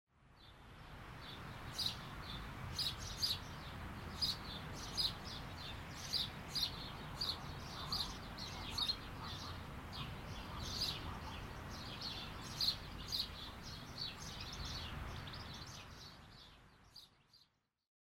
PAJAROS EN EL PARQUE
Tonos EFECTO DE SONIDO DE AMBIENTE de PAJAROS EN EL PARQUE
Pajaros_en_el_parque.mp3